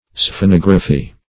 Search Result for " sphenography" : The Collaborative International Dictionary of English v.0.48: Sphenography \Sphe*nog"ra*phy\, n. [Gr. sfh`n a wedge + -graphy.]